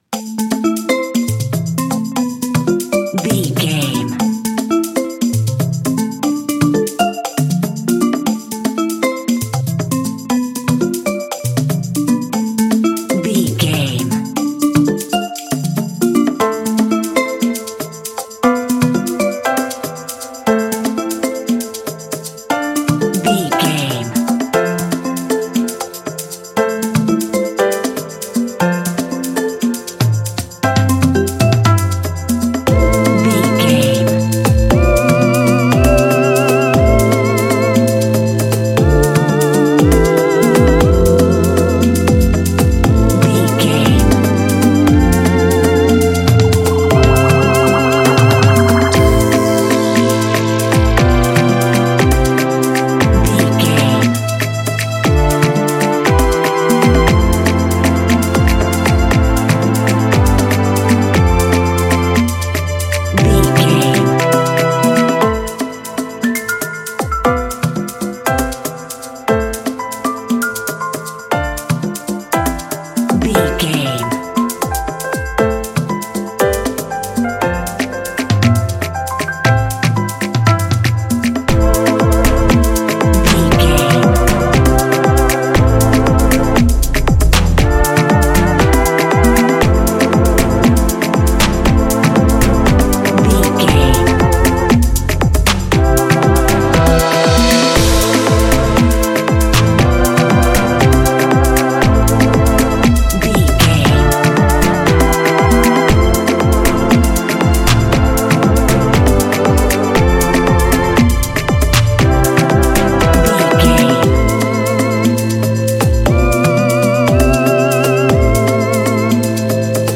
Uplifting
Ionian/Major